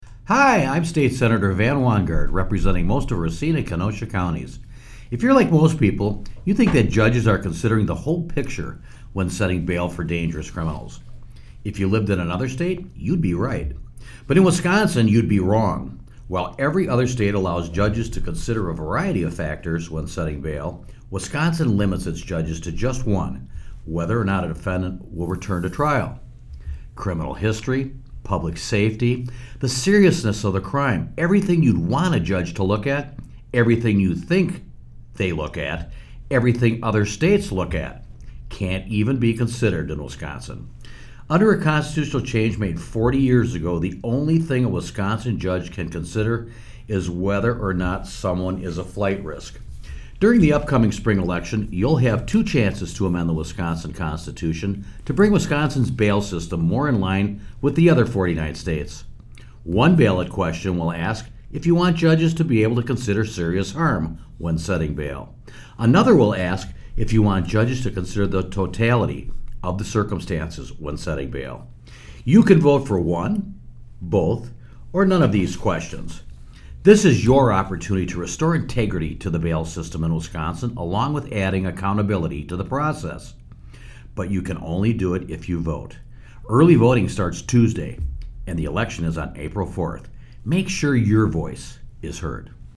Weekly GOP radio address: Sen. Wanggaard talks about reforming Wisconsin's broken bail system - WisPolitics